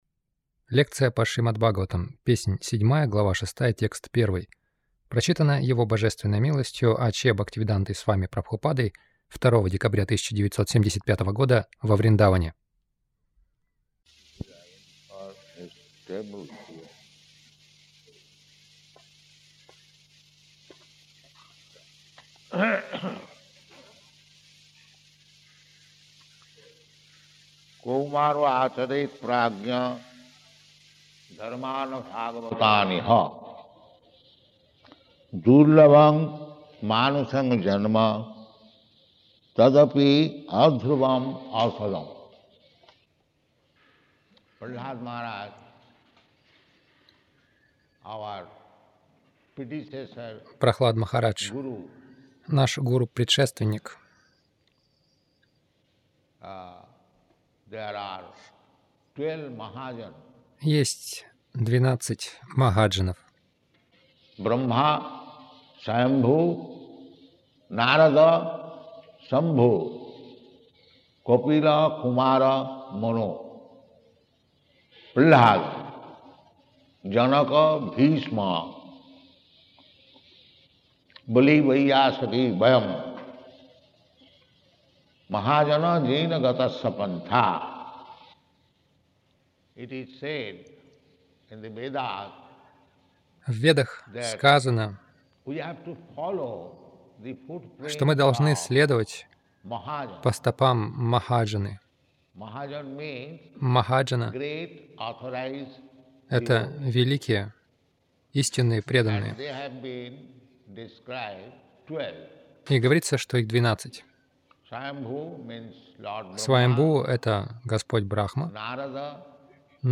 Милость Прабхупады Аудиолекции и книги 02.12.1975 Шримад Бхагаватам | Вриндаван ШБ 07.06.01 — С детства следуйте Бхагавата-дхарме Загрузка...